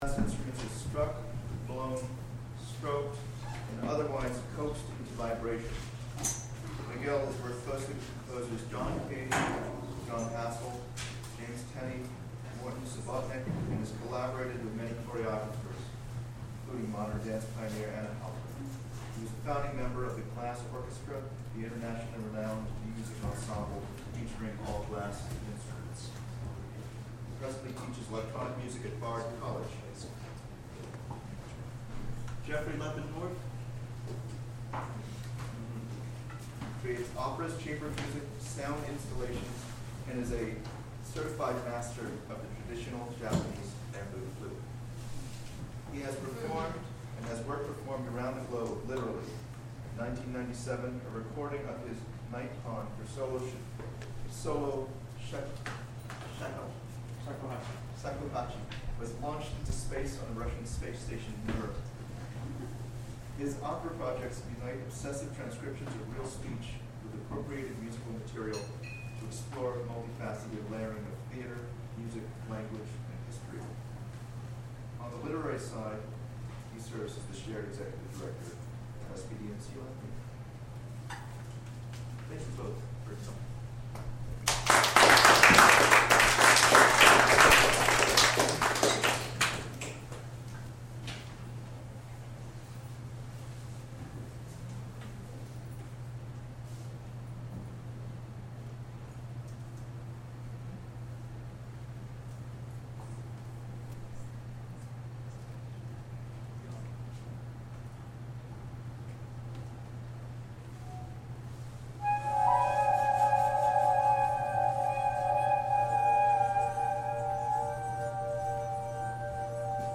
performing at Athens Cultural Center